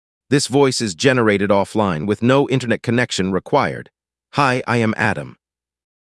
- High-Quality Audio: Delivers clear and expressive voices with a natural tone.
Sample Audio Voices: